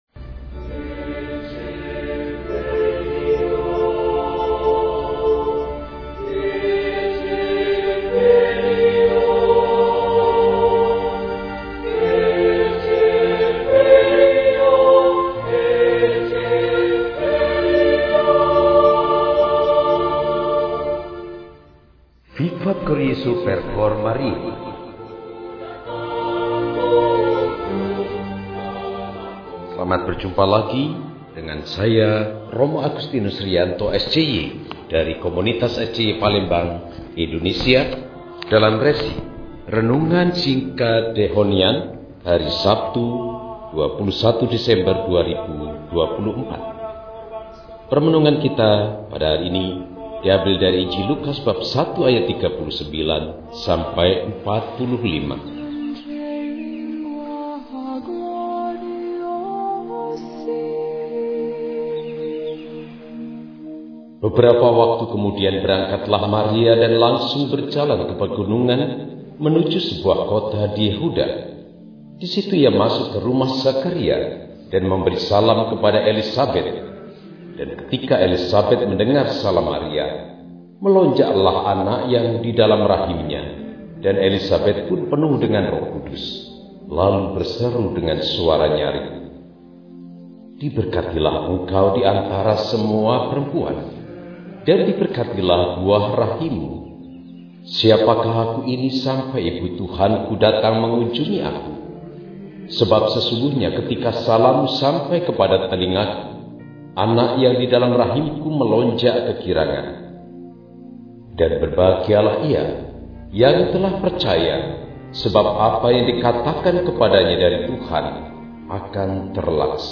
Sabtu, 21 Desember 2024 – Hari Biasa Khusus Adven – RESI (Renungan Singkat) DEHONIAN